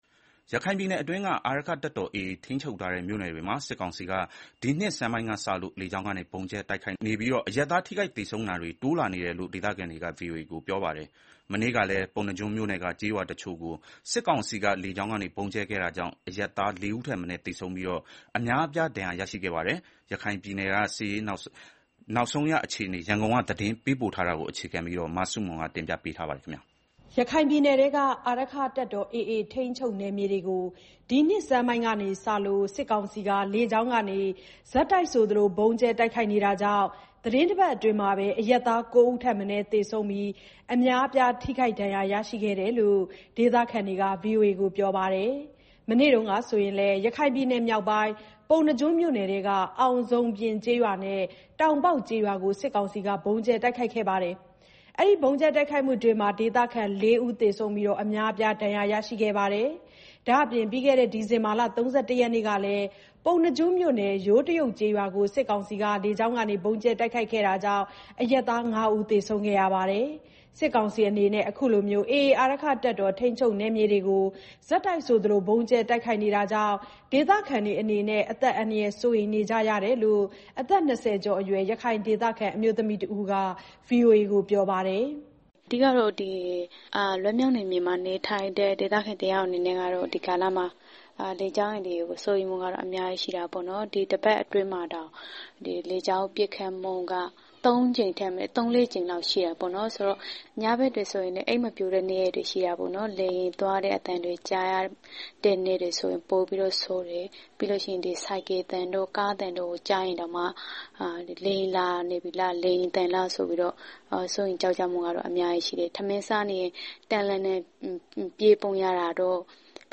ရခိုင်ပြည်နယ်အတွင်းက အာရက္ခတပ်တော် AA ထိန်းချုပ်ထားတဲ့ မြို့နယ်တွေမှာ စစ်ကောင်စီက ဒီနှစ်ဆန်းပိုင်းကစလို့ လေကြောင်းကနေ ဆက်တိုက်ဗုံးကြဲနေလို့ အရပ်သားထိခိုက်သေဆုံးတာတွေ တိုးလာနေတယ်လို့ ဒေသခံတွေက ဗွီအိုအေကိုပြောပါတယ်။ မနေ့ကလည်း ပုဏ္ဏားကျွန်းမြို့နယ်က ကျေးရွာတချို့ကို စစ်ကောင်စီက လေကြောင်းကနေ ဗုံးကြဲခဲ့တာကြောင့် အရပ်သားလေးဦးထက် မနည်းသေဆုံးပြီး အများအပြား ဒဏ်ရရှိခဲ့ပါတယ်။ ရခိုင်ပြည်နယ်က စစ်ရေးနောက်ဆုံးအခြေအနေ ရန်ကုန်က သတင်းပေးပို့ထားပါတယ်။